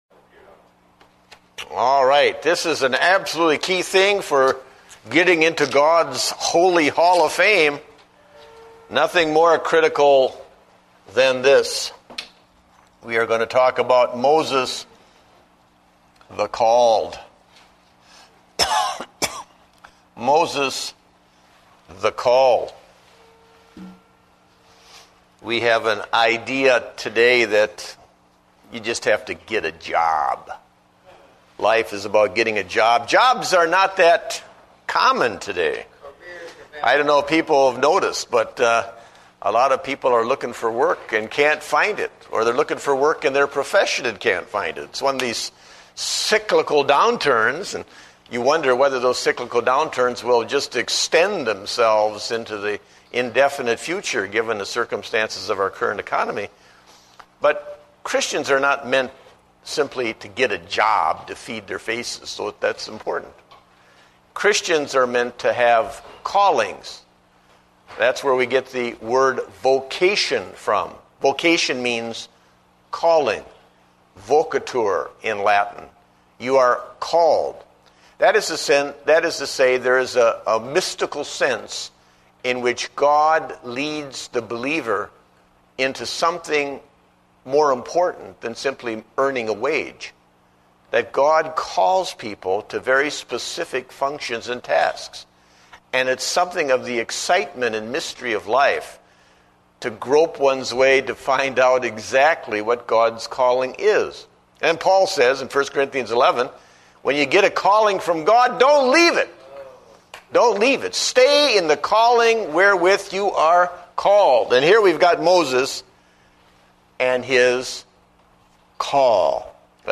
Date: June 21, 2009 (Adult Sunday School)